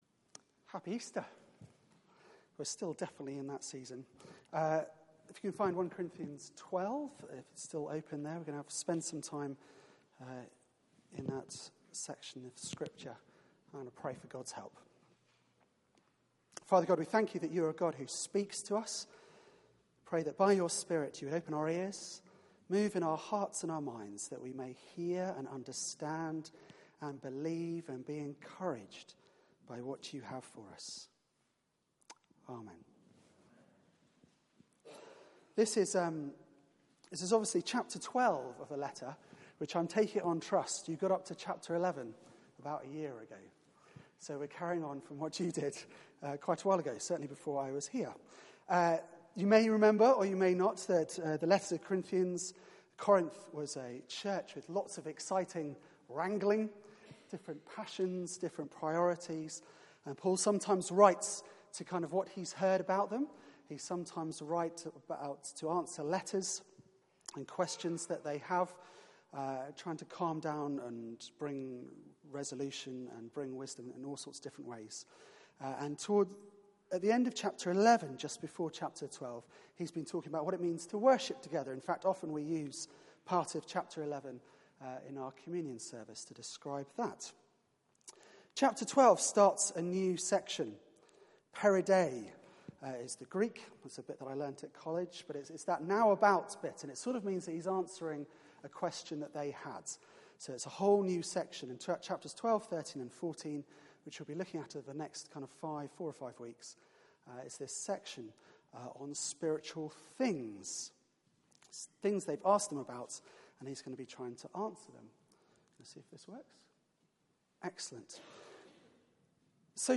Media for 4pm Service on Sun 10th Apr 2016 16:00 Speaker